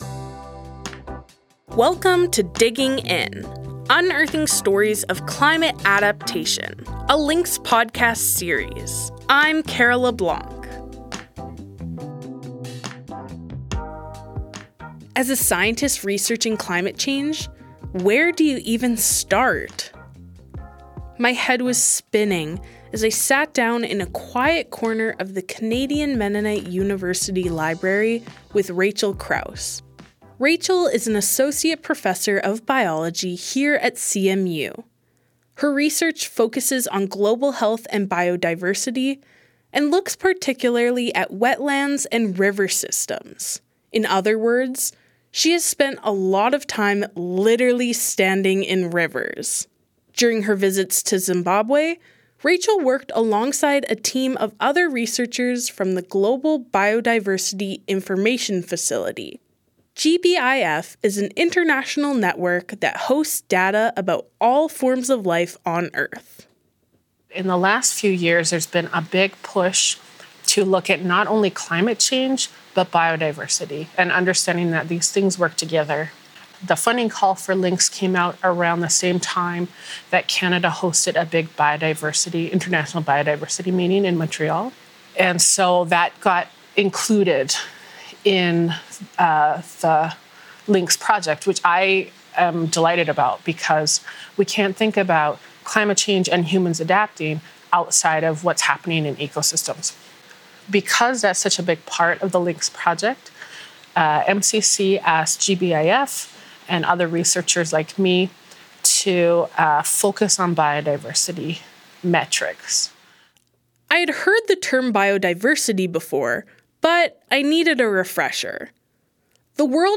interviews different CMU researchers about their on-the-ground research in Zimbabwe